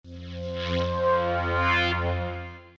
На этой странице собраны оригинальные звуки Windows 95: старт системы, уведомления, ошибки и другие знакомые мелодии.
Windows 95 звук завершения работы из темы Robotz